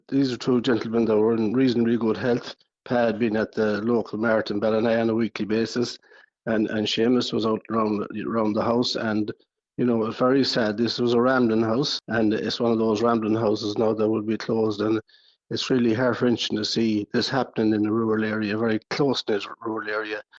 Sligo Councillor Michael Clarke has been remembering the brothers: